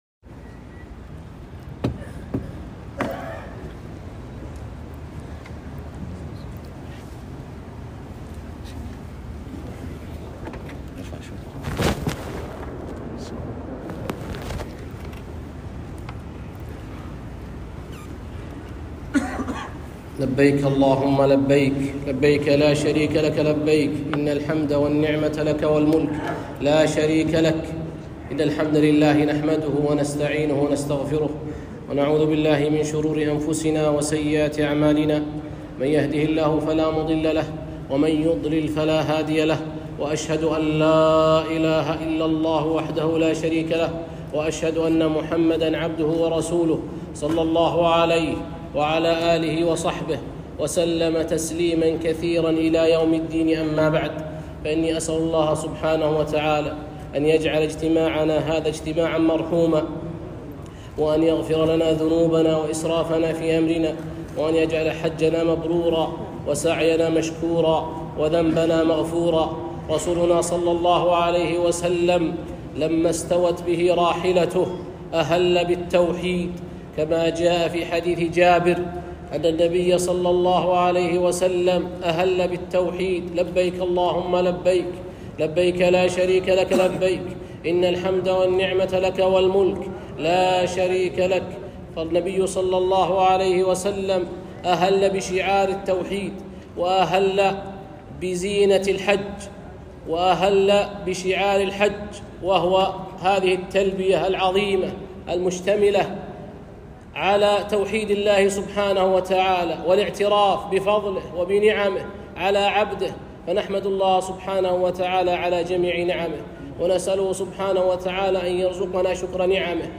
محاضرة - معالم التوحيد في الحج